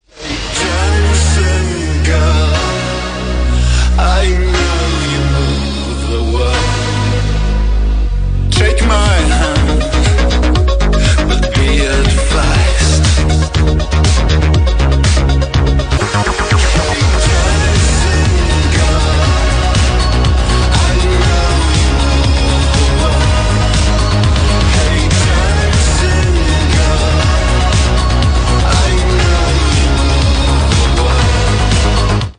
Мужской голос, что-то там про "dancing".